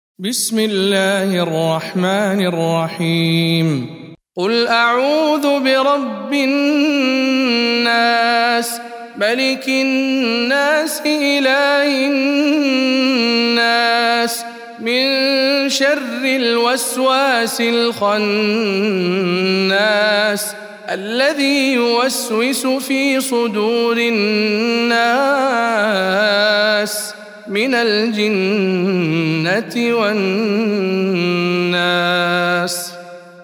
سورة الناس - رواية ابن وردان عن أبي جعفر